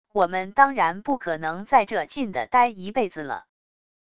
我们当然不可能在这禁地呆一辈子了wav音频生成系统WAV Audio Player